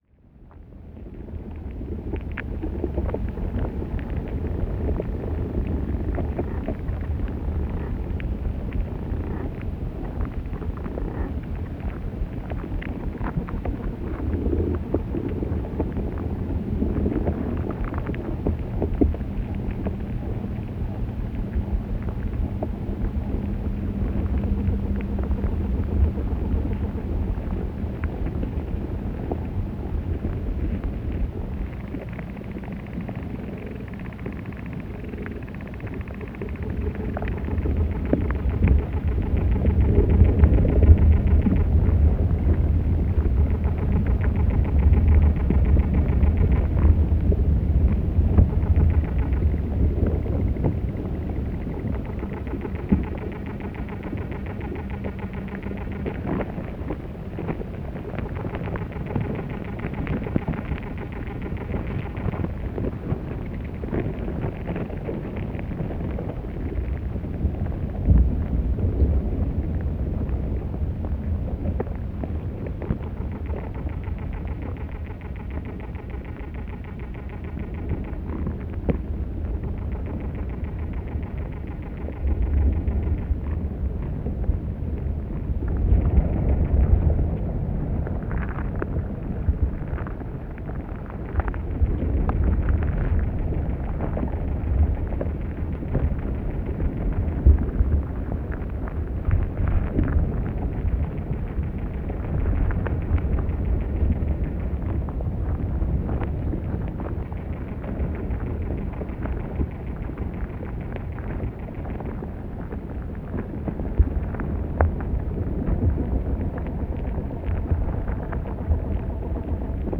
is a multi-species chorus
composed of field recordings made in a wild-grafted apple orchard outside of Hudson, NY in June 2024
The week of the summer solstice in June, community members gathered for a durational deep listening event in an apple orchard. Participants spent several minutes listening and then (after a child shook a tambourine), they were invited to make sounds and motions inspired by the sounds they had tuned into in the orchard.
The chorus moves from the ground up: from the liquid, bugs and organisms in the soil beneath the trees, to the grasses, the trees, leaves in the wind, to the human bodies, breath and voices gathered on the ground in the grass, up to the birds in the sky and then a final layer of the chorus—the sounds of the voices, bodies and rhythms of our individual memories and reminiscences as well as the collective memory of the community and the land as represented by the archival recordings.